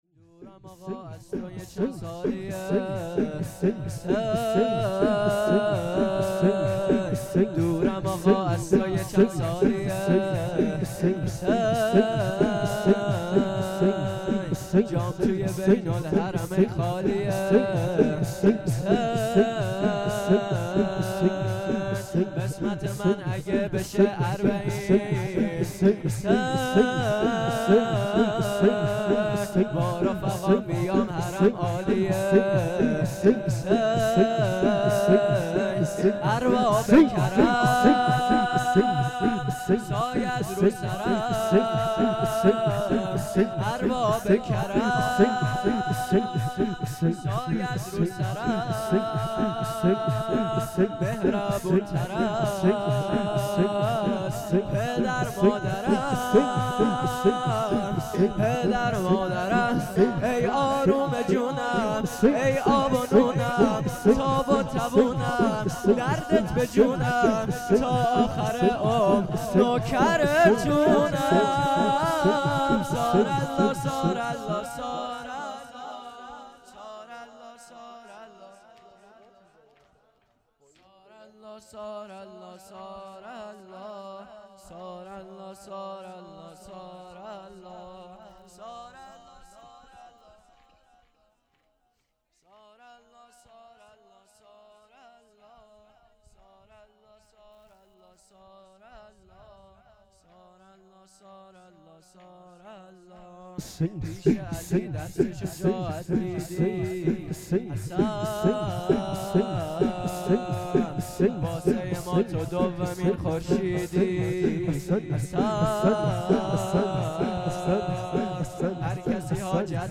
هیئت مکتب الرضا علیه السلام دلیجان
شور
مسجد امام موسی بن جعفر علیه السلام | 12 دی ماه 1397| شهادت حضرت زهرا سلام الله علیها